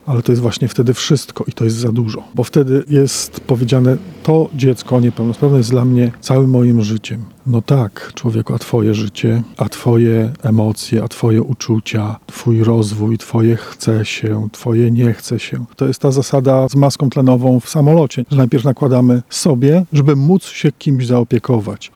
Do zadbania o siebie, a przez to także o swoją rodzinę zachęcają rodziców niepełnosprawnych dzieci prelegenci podczas konferencji w Benedyktyńskim Zakątku w Puławach.